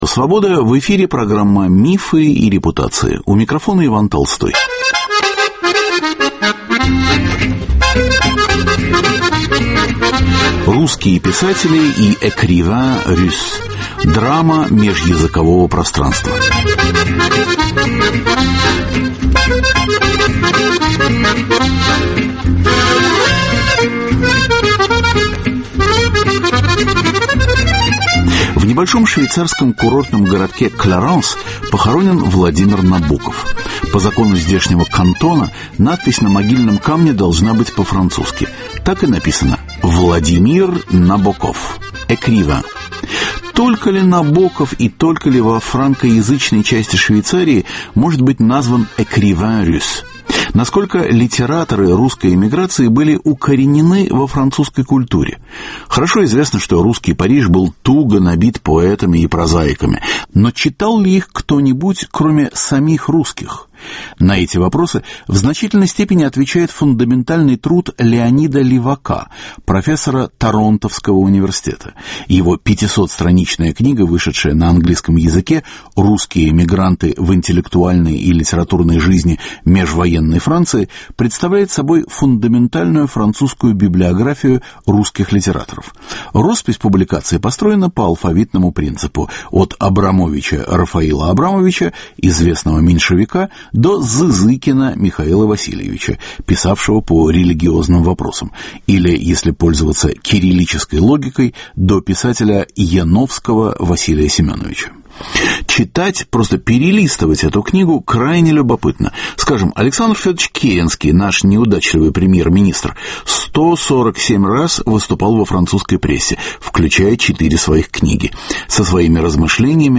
Кого из русских литераторов 19-20 веков можно назвать наиболее французским писателем? Участвуют французские слависты, университетские профессора из Франции, Швейцарии и Канады.